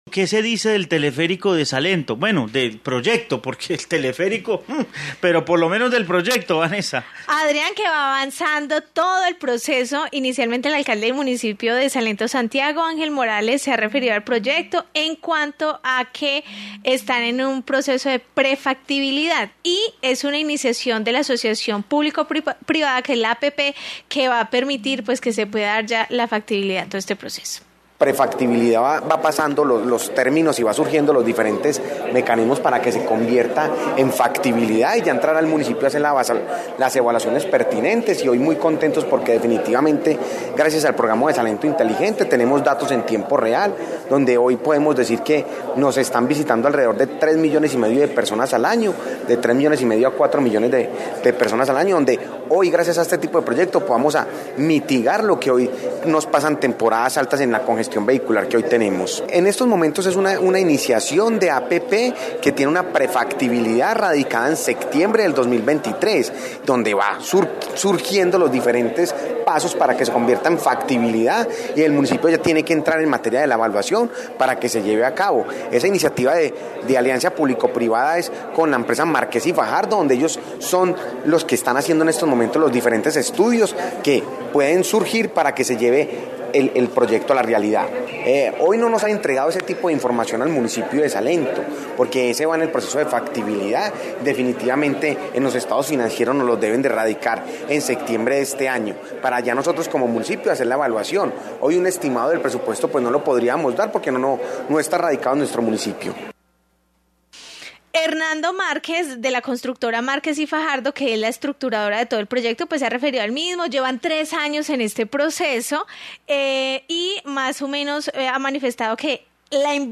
Informe sobre teleférico de Salento